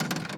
Tool sounds
tool_bonk_v0.wav